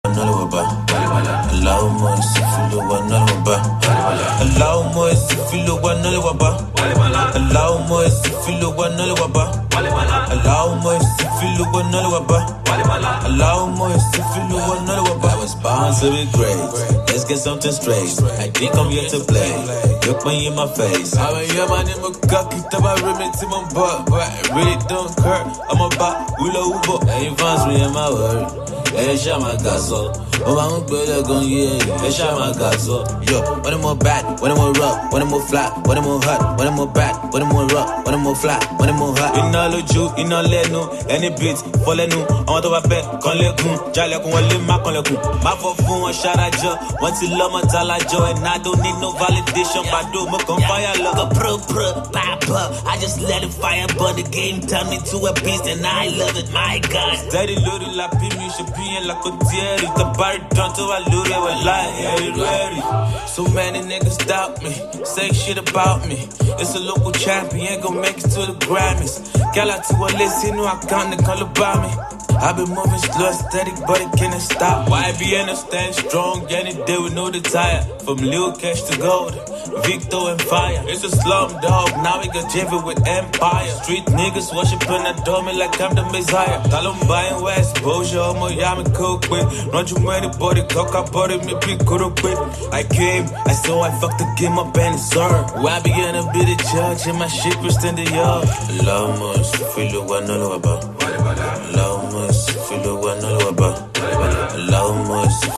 Nigeria music freestyle